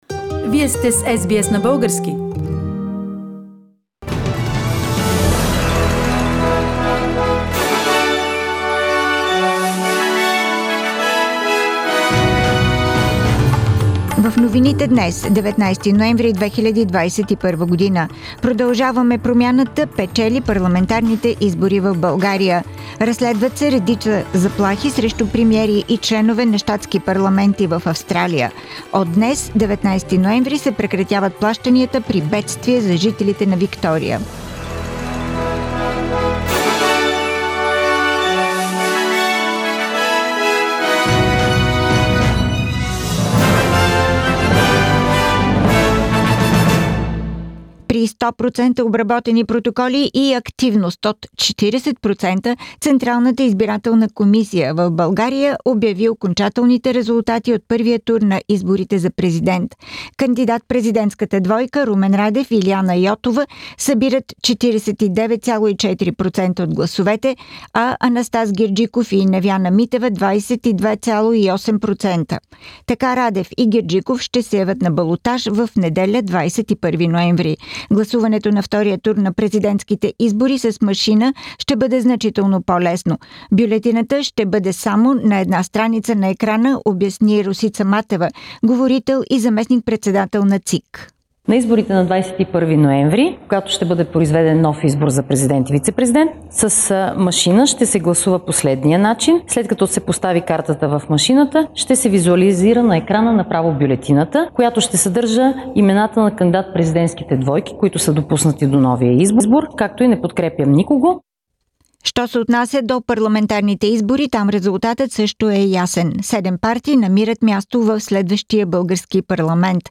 Weekly Bulgarian News – 19th Nov 2021